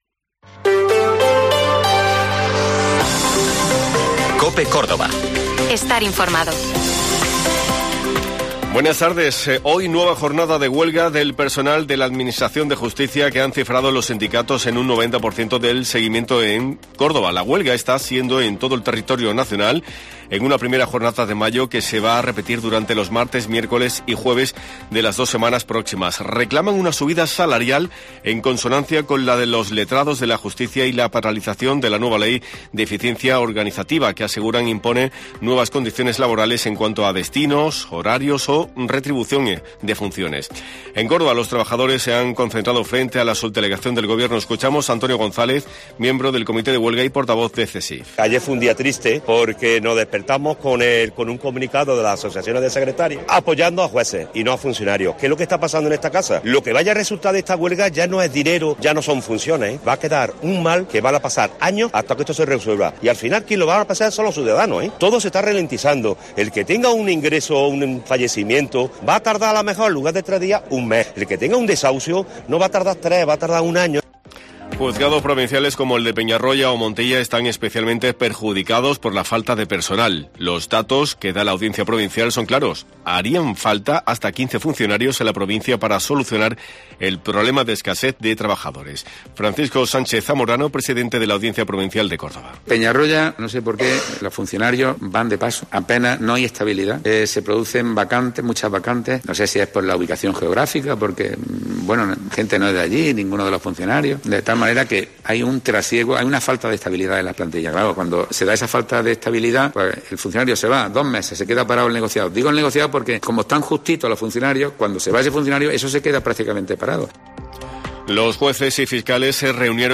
Informativo Mediodía COPE Córdoba de 14:20 a 14:30h (04/05/2023)